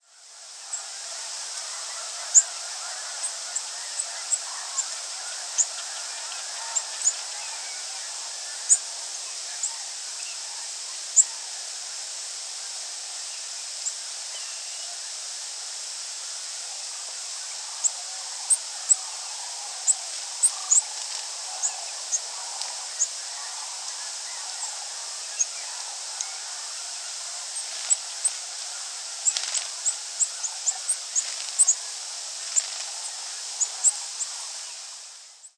Orange-crowned Warbler diurnal flight calls
Diurnal calling sequences:
Two birds in flight. Snow Geese and Black-bellied Plover calling in the background.